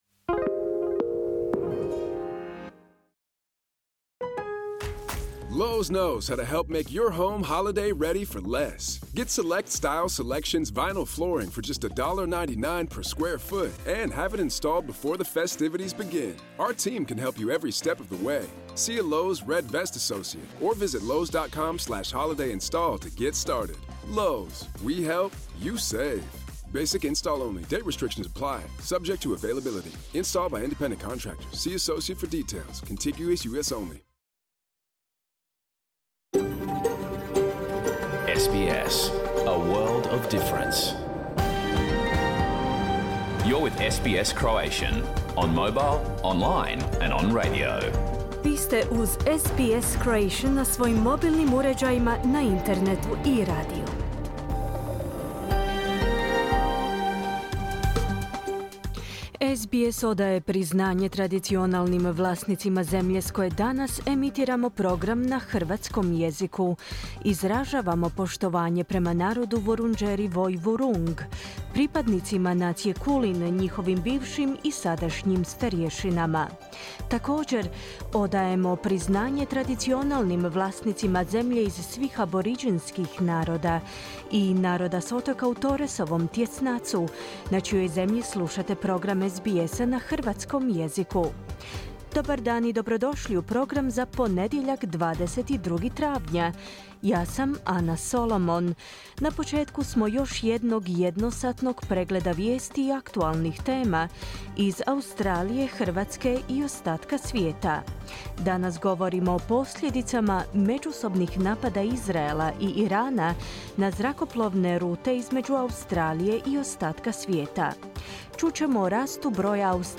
Pregled vijesti i aktualnih tema iz Australije, Hrvatske i ostatka svijeta. Emitirano uživo na radiju SBS, u ponedjeljak, 22. travnja 2024., s početkom u 11 sati po istočnoaustralskom vremenu.